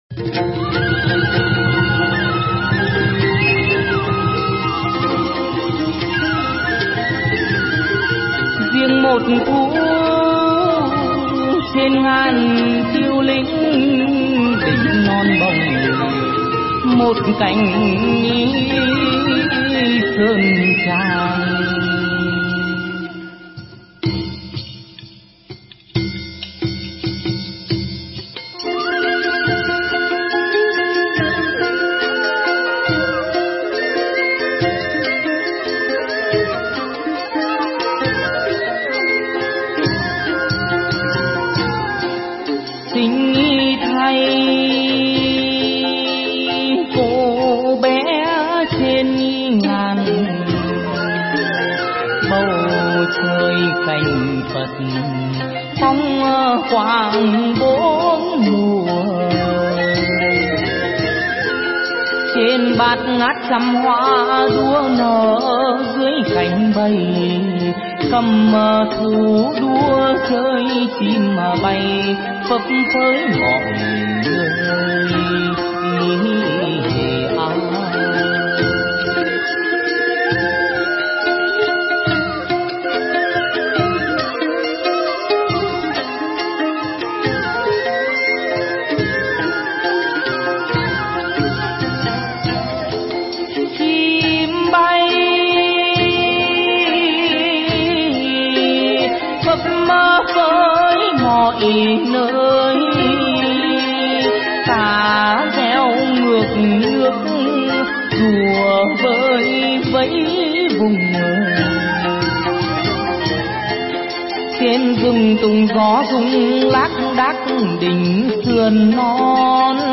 Hát Chầu Văn